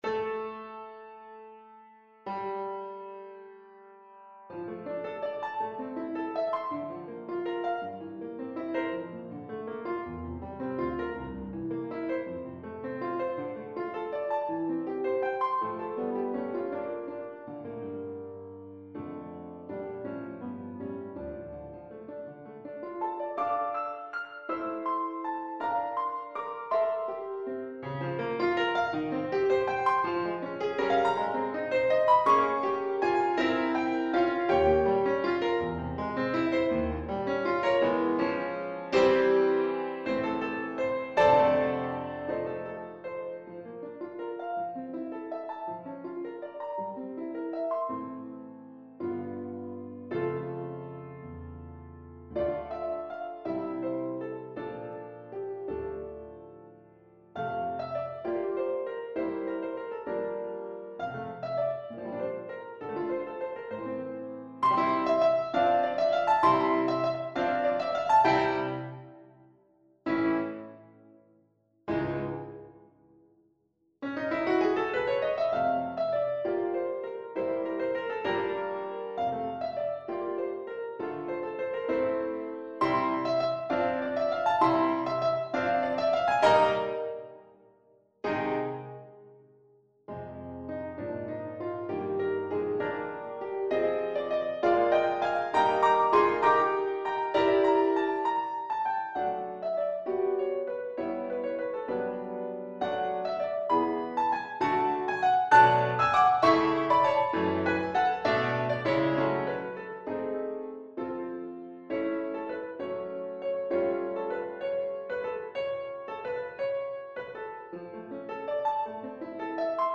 6/8 (View more 6/8 Music)
Classical (View more Classical French Horn Music)